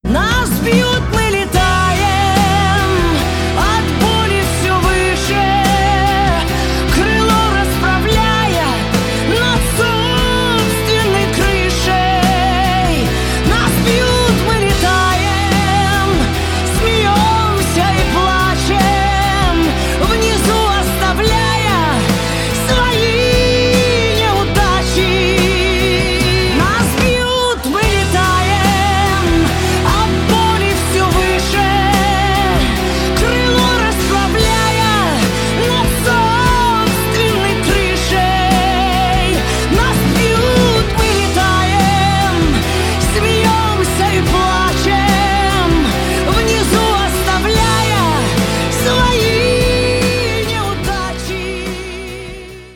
• Качество: 320, Stereo
поп
женский вокал
грустные
сильные
красивый женский вокал